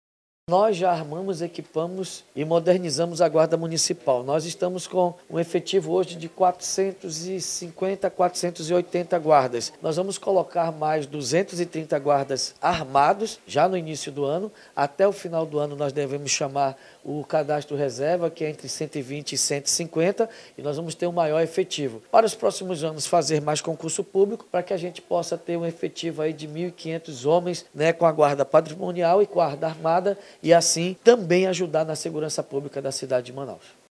Um dia após a reeleição, o prefeito de Manaus, David Almeida, participou, nessa segunda-feira (28), de uma série de entrevistas a emissoras de rádio e TV, onde pontuou quais serão as prioridades para os próximos quatro anos de gestão.
01-SONORA-PREFEITO-DAVID-ALMEIDA.mp3